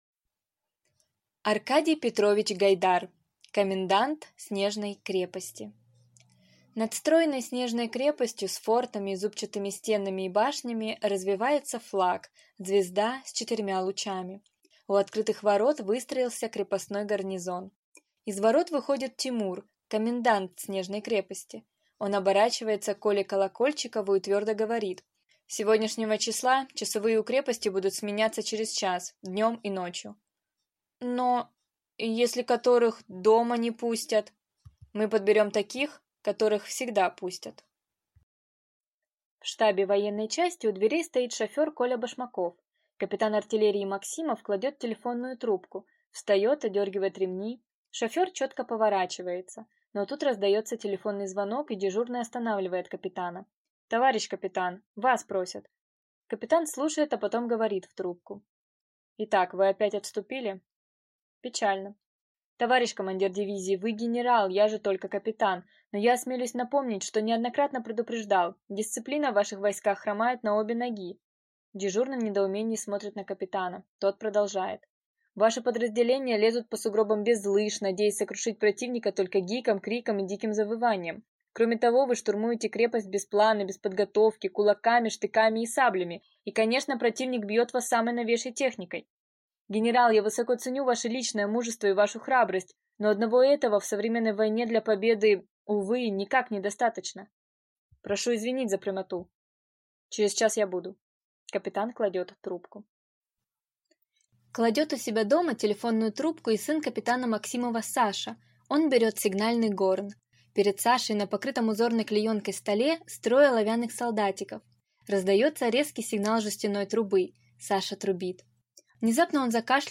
Аудиокнига Комендант снежной крепости | Библиотека аудиокниг